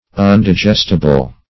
Undigestible \Un`di*gest"i*ble\
undigestible.mp3